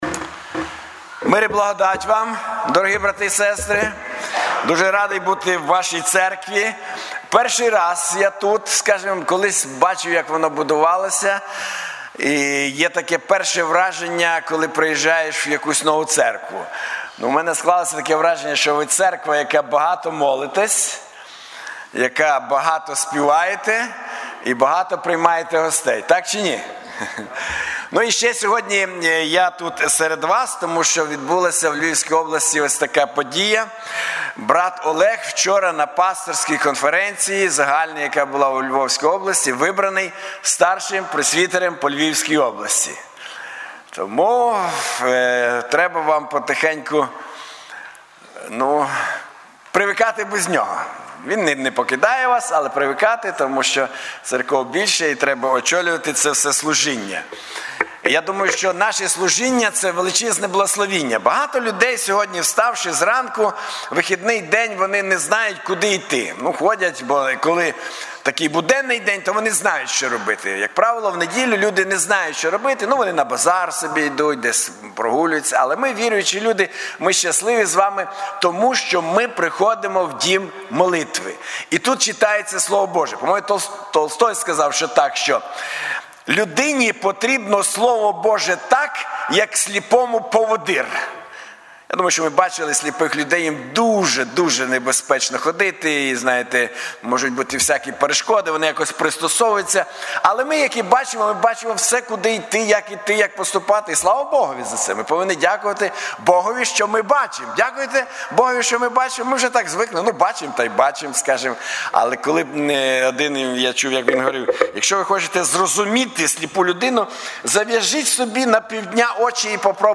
Трускавець Церква Преображення